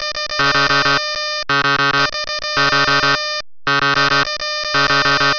shortalarm.wav